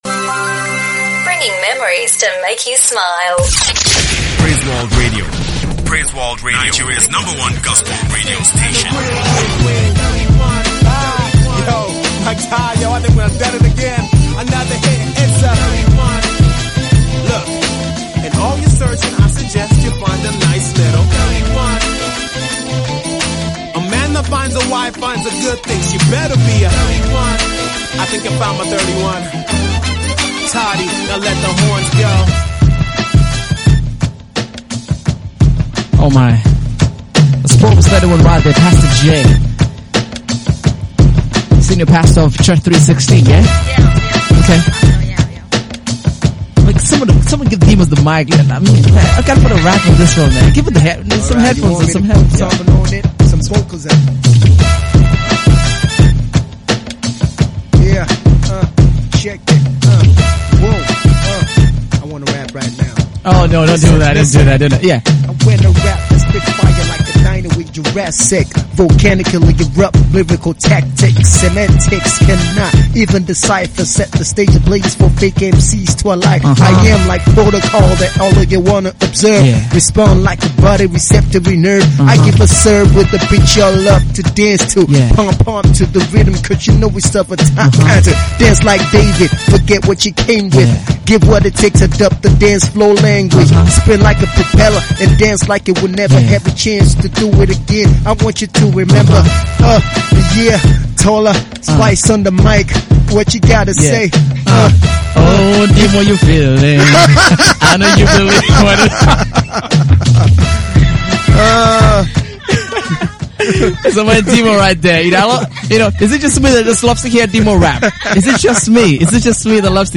Christian Hip Hop